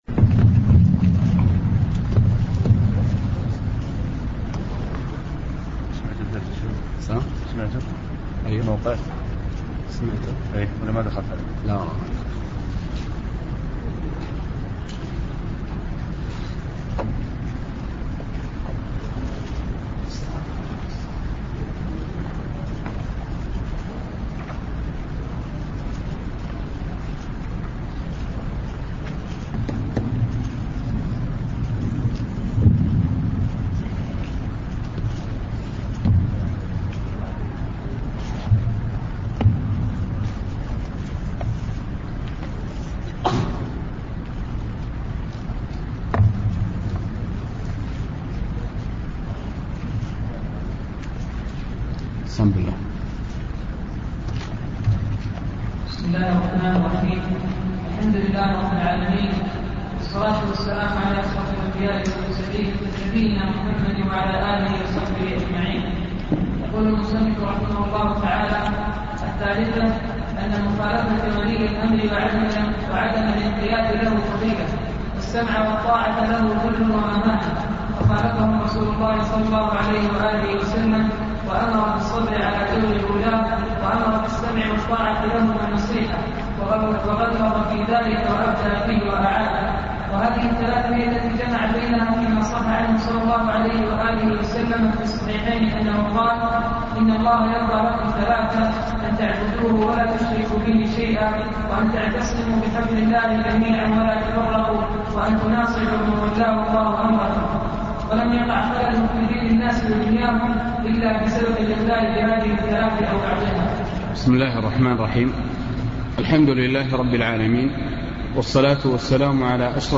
ضمن الفصل الأول للدورة العلمية المكثفة الثانية بجامع القاضي بعنيزة